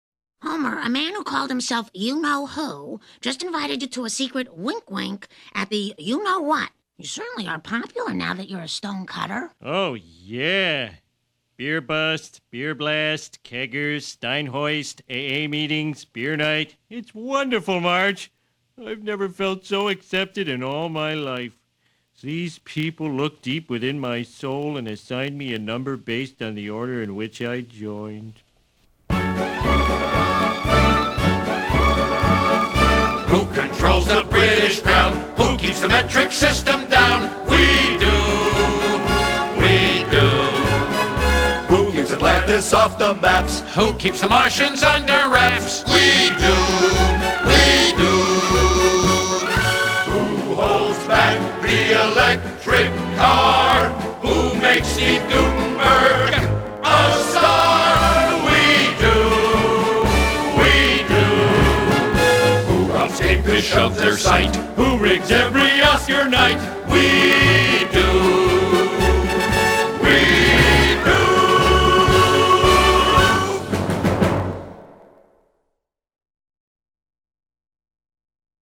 Hino: Windows Media (